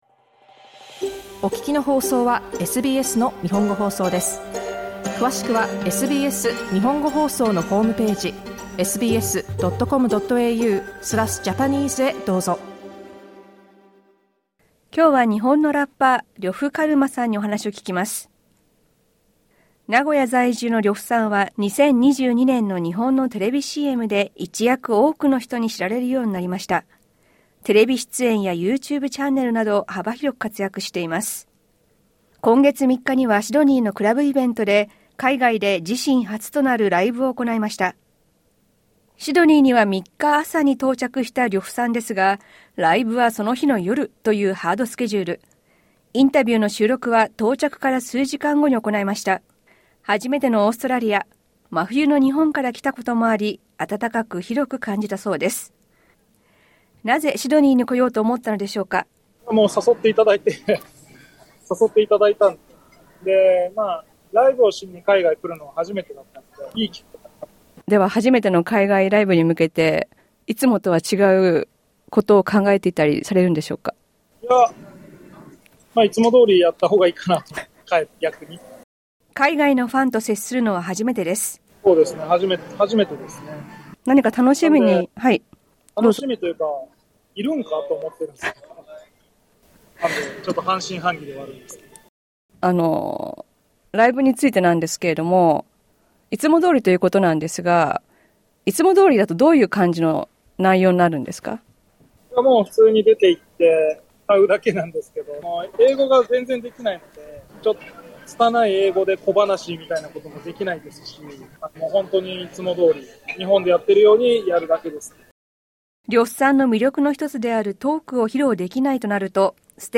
シドニーでのライブや自身の音楽の魅力、家庭を持ったことによる変化などについてお話を聞きました。 インタビューはシドニー到着当日、ライブ開催前に行いました。